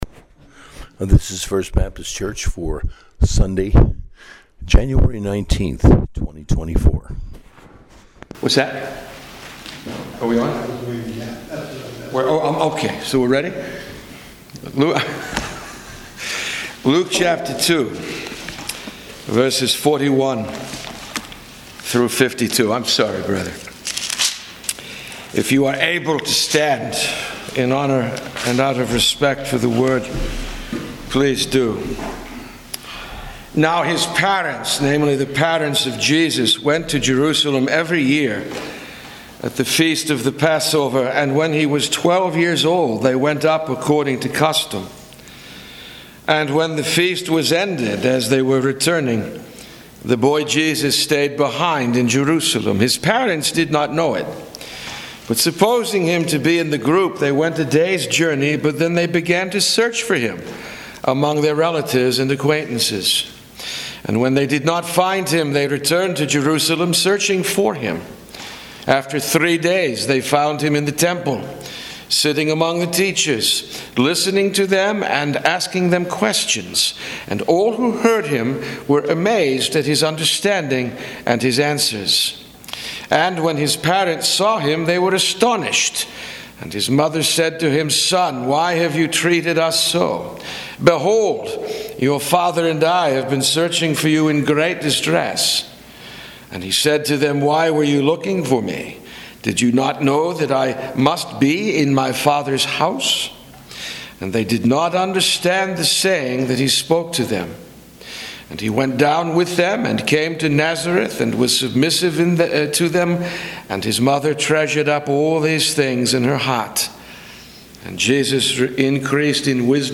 Sunday Sermon, taken from Gospel of Luke 2:41-52